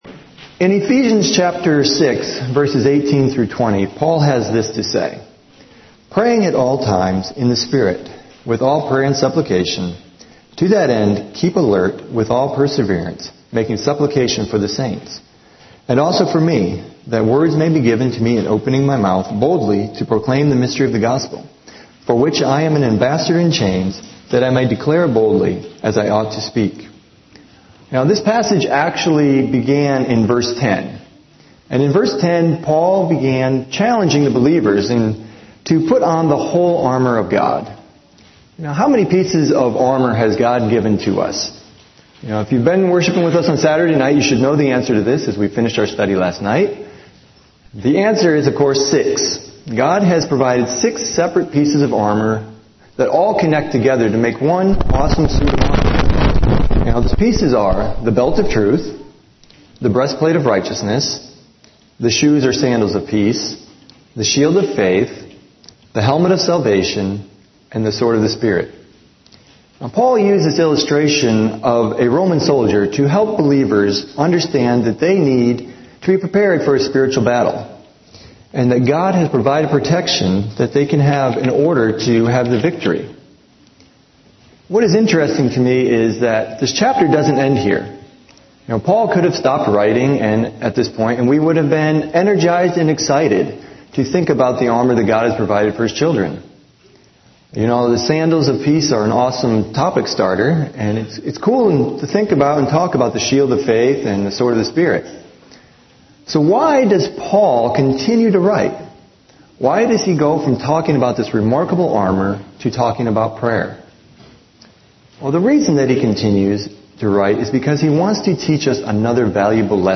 Audio recordings, transcripts and Youtube link of Bible messages shared at OIC.